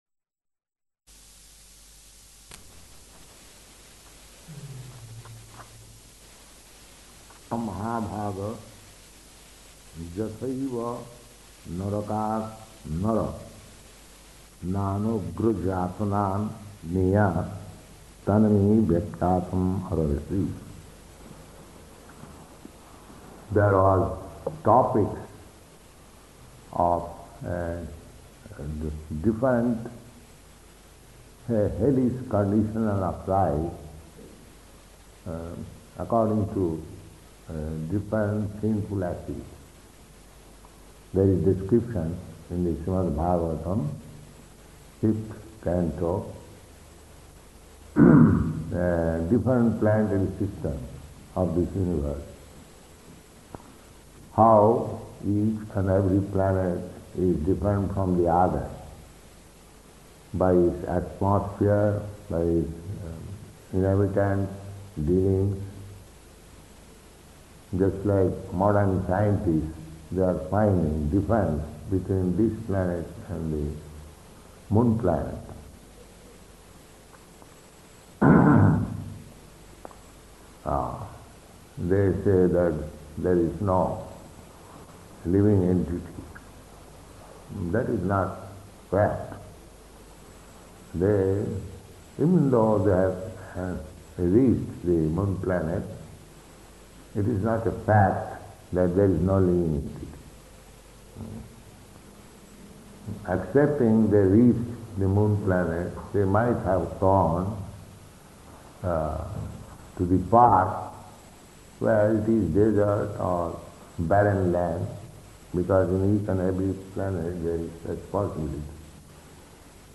Location: Bombay